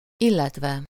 Ääntäminen
Synonyymit és valamint Ääntäminen Tuntematon aksentti: IPA: /ˈilːɛtvɛ/ Lyhenteet ja supistumat ill.